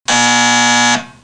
edbuzzer.mp3